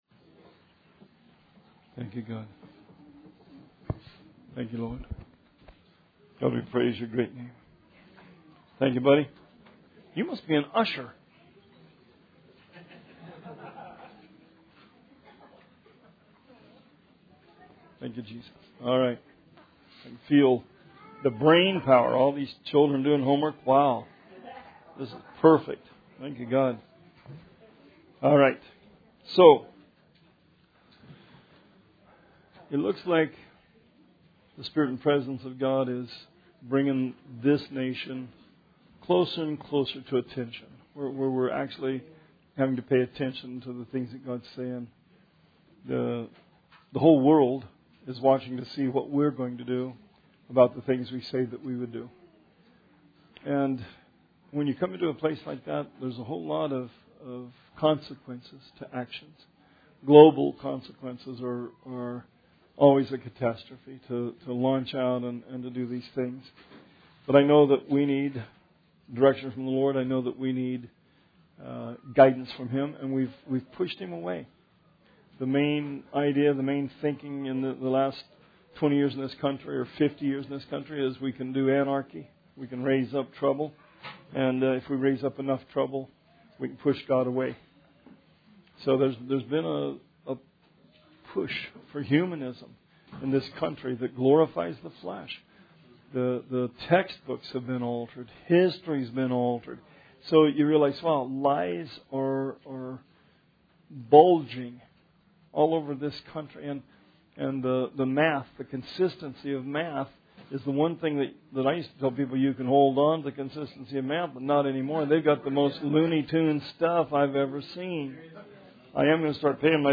Bible Study 9/6/17